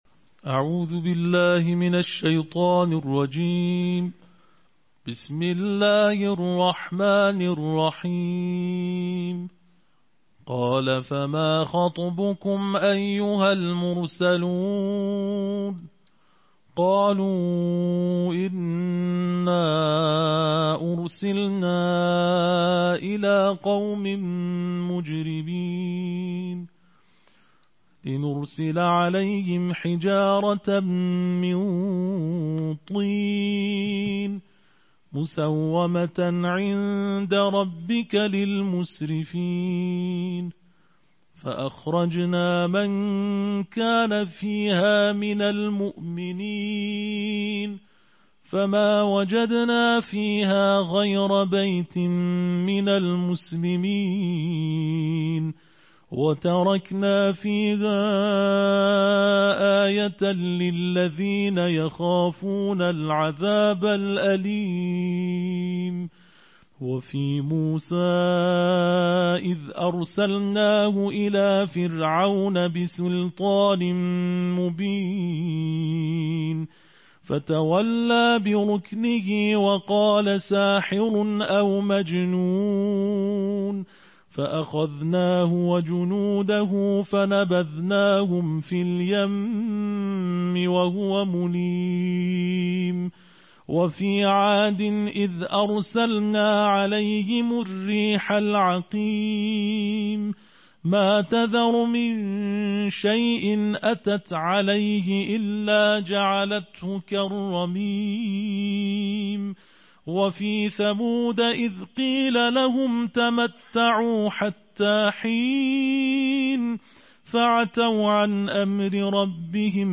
IQNA - Sertai IQNA setiap hari sepanjang bulan Ramadhan yang penuh keberkatan untuk perjalanan rohani melalui mengkhatamkan Al-Quran hingga akhir bulan. Anda boleh mendengar Pembacaan Tartil Juzuk 27 Al-Quran di bawah ini.